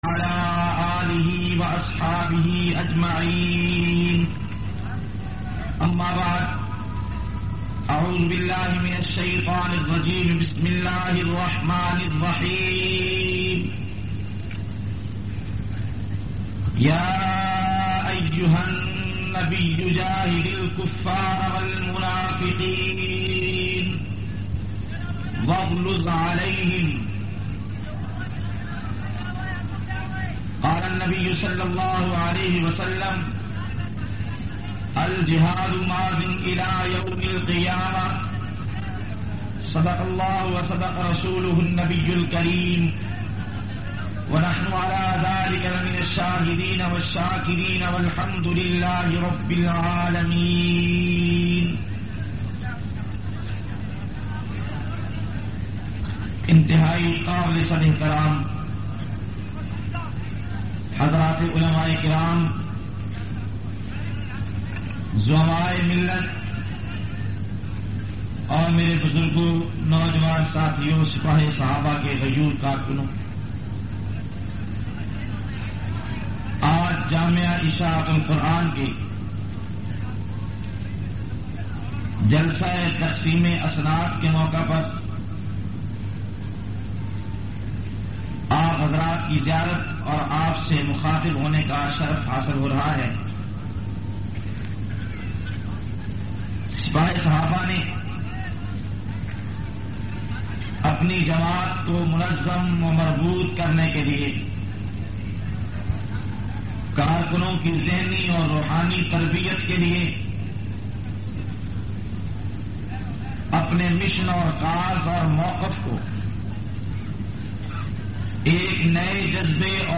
692- Salana Jalsa Dastarbandi-Jamia Alvia Ishaat Ul  Quran.mp3